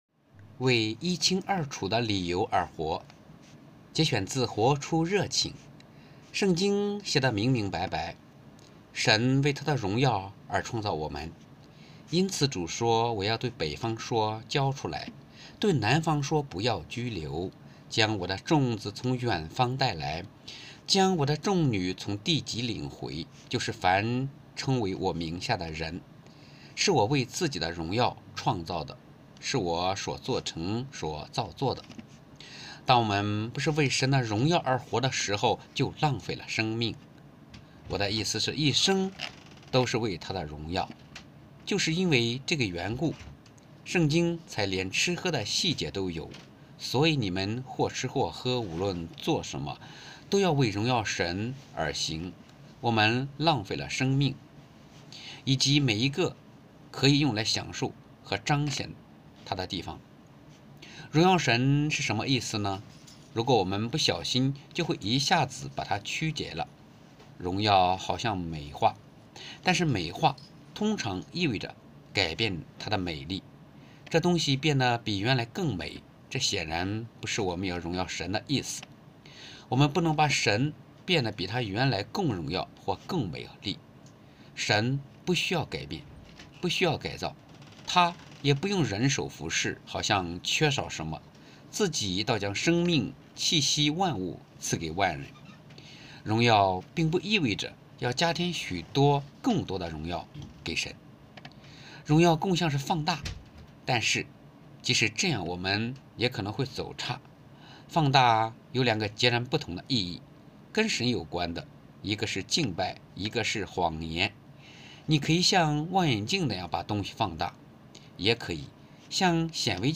2023年5月24日 “伴你读书”，正在为您朗读：《活出热情》 本周章节： 作者：约翰·派博（John Piper） 译者：张书筠 别浪费生命！活出热情的人生！